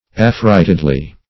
affrightedly - definition of affrightedly - synonyms, pronunciation, spelling from Free Dictionary Search Result for " affrightedly" : The Collaborative International Dictionary of English v.0.48: Affrightedly \Af*fright"ed*ly\, adv.